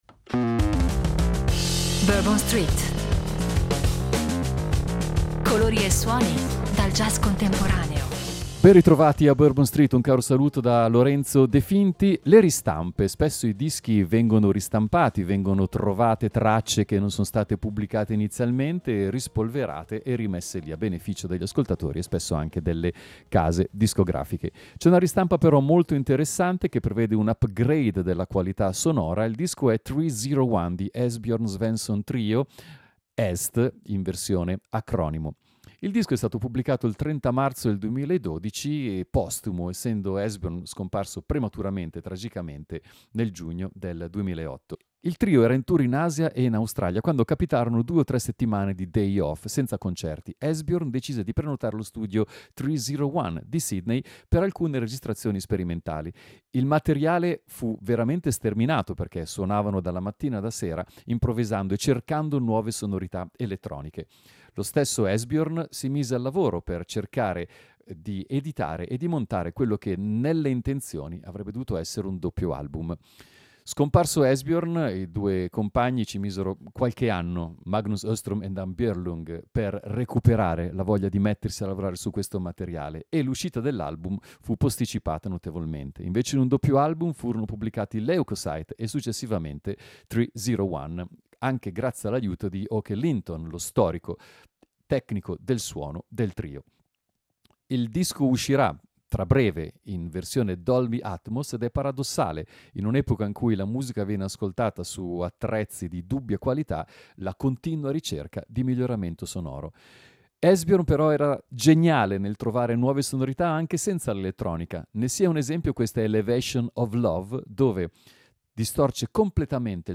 Vecchio e nuovo insieme, spinta verso il futuro coniugata con il più totale rispetto della propria storia: colori e suoni dal jazz contemporaneo insomma.